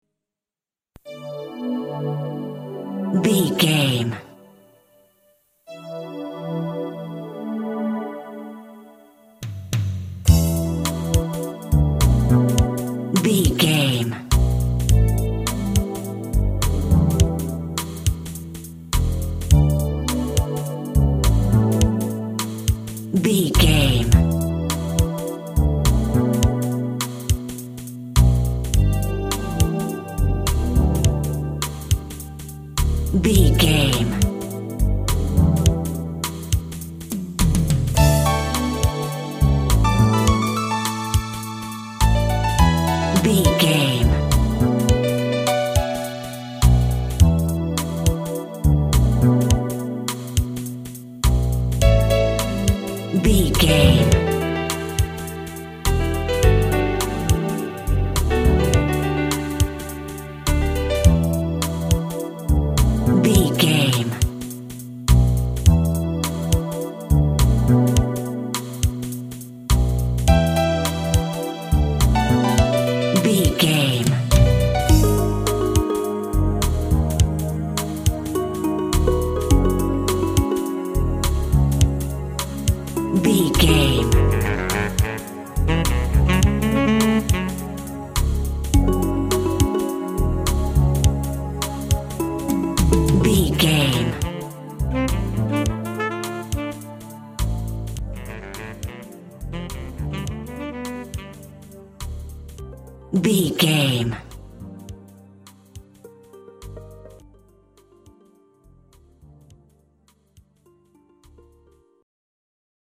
Aeolian/Minor
D
Slow
melancholy
hypnotic
dreamy
groovy
drums
synthesiser
saxophone
80s
Retro
electronic
techno
synth bass
synth lead